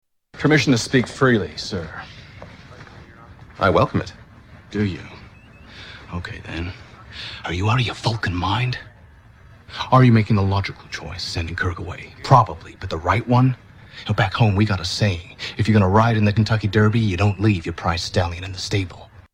Category: Movies   Right: Personal
Tags: Star Trek Bones McCoy - Star Trek 2009 Star Trek 2009 Bones McCoy clips Karl Urban